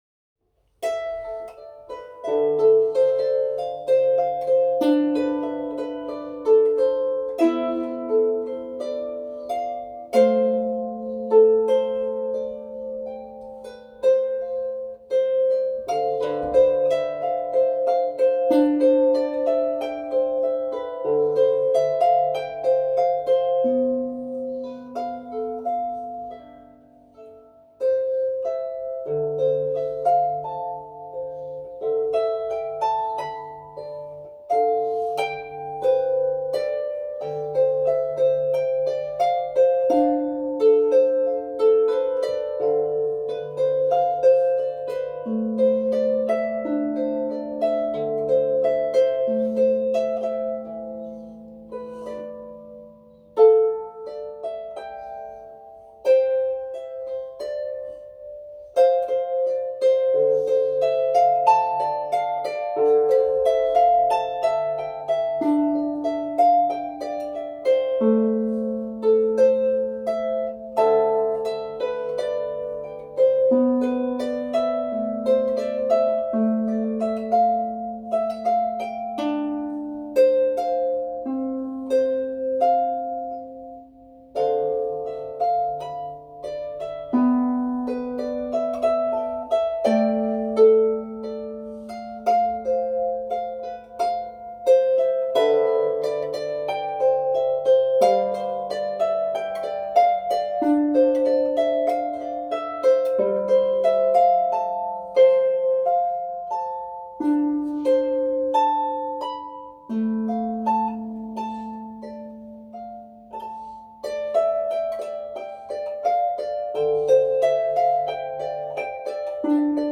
Tonbeispiel Harfe